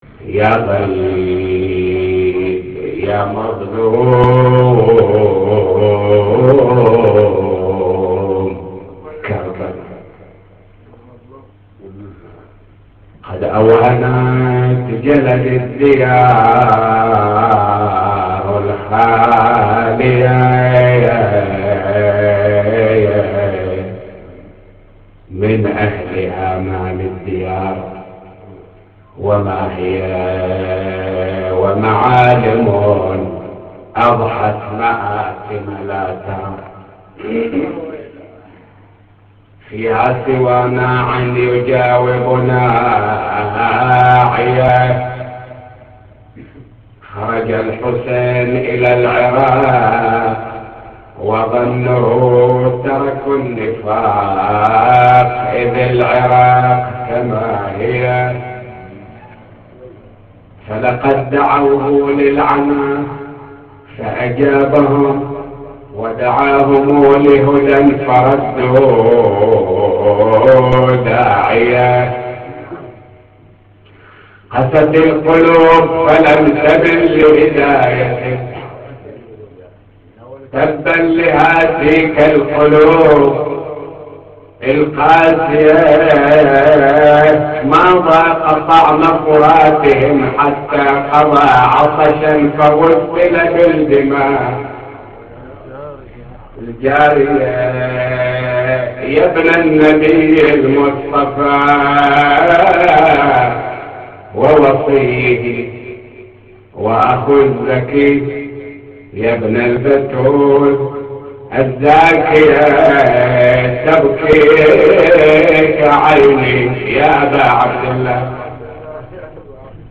نواعي وأبيات حسينية – 1